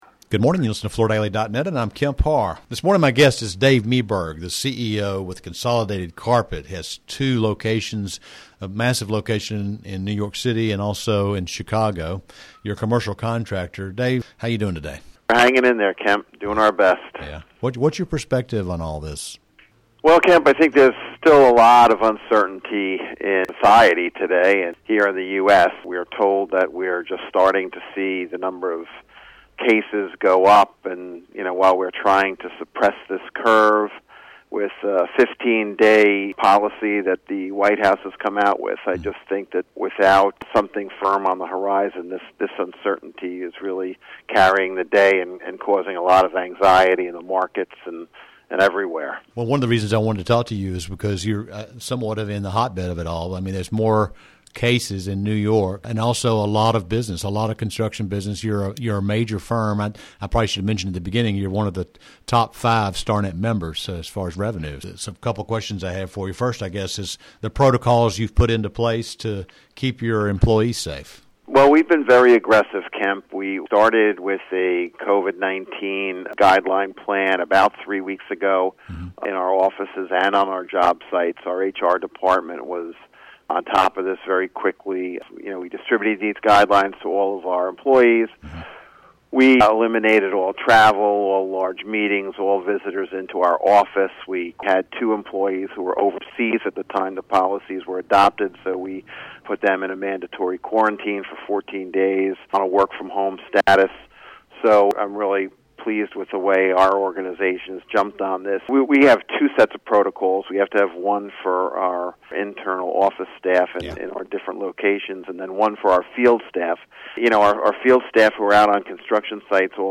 Listen to the interview for more details on the steps he is taking to keep his employees safe and keep his consumer’s projects moving forward.